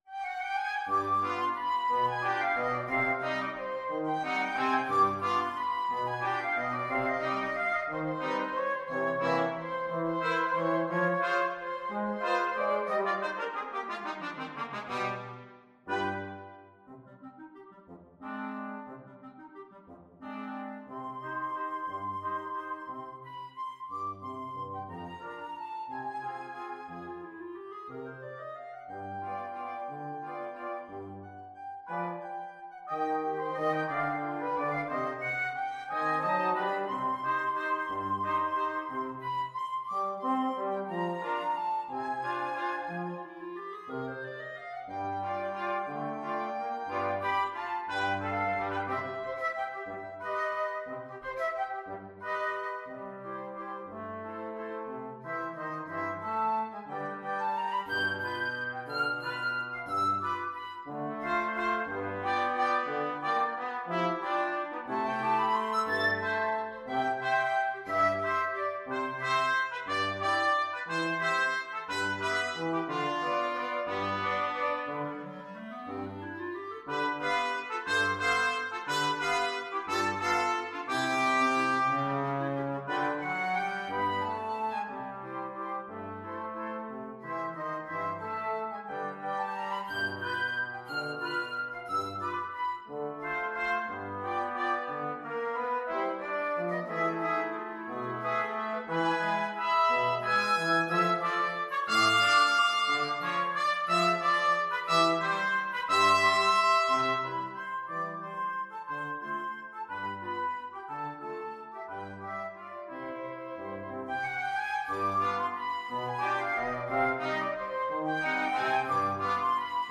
Flute
Trumpet
Clarinet
Trombone
Euphonium
Allegro movido =180 (View more music marked Allegro)
3/4 (View more 3/4 Music)
Mexican